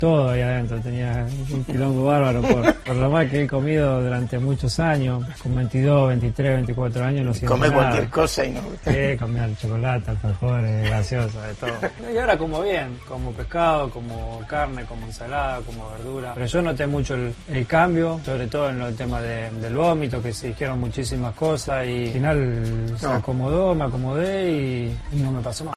En una entrevista grabada a principios de febrero en la oficina de Messi en Barcelona, emitida anoche en América Televisión, hablaron de todos los temas personales del jugador, incluidos sus hábitos alimenticios.